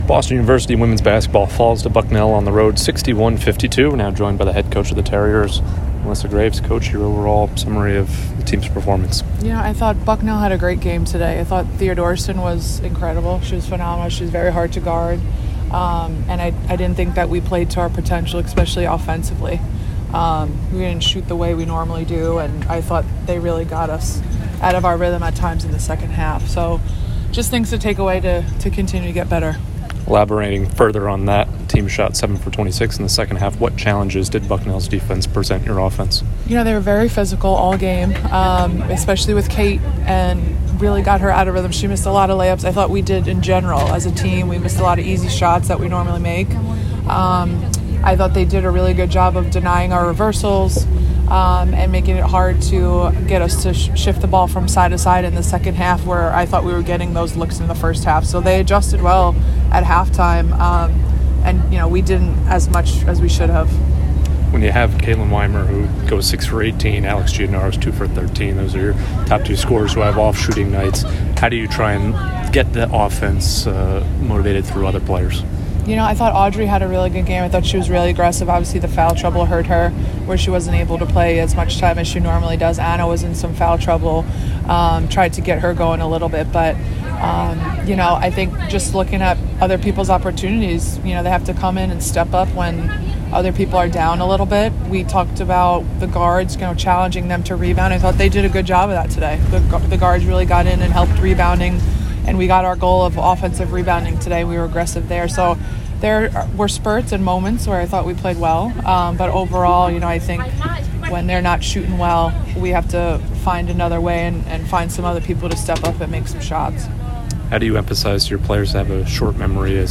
Women's Basketball / Bucknell Postgame Interview (1-17-24) - Boston University Athletics